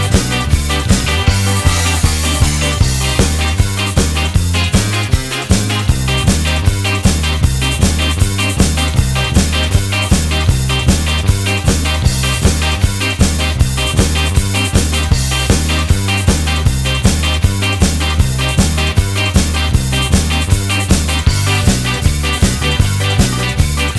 for Sax Ska 2:21 Buy £1.50